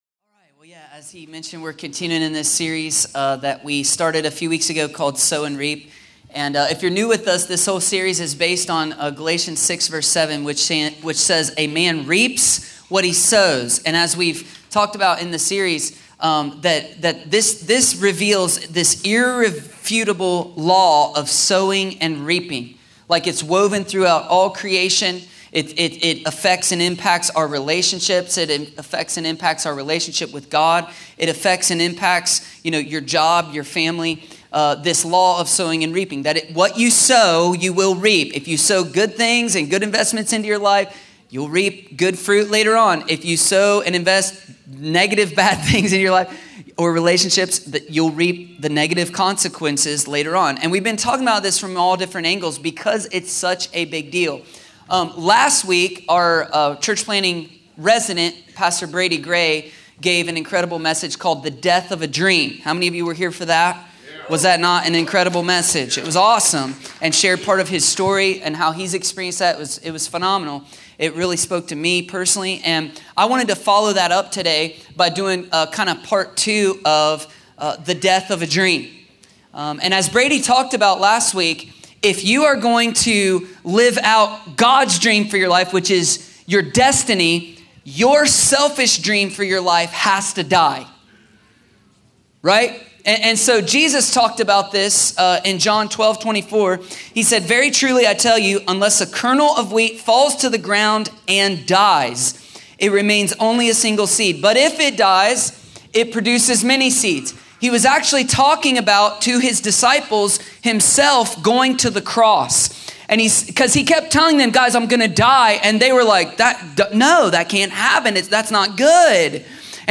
A sermon from the series “Sow & Reap.”…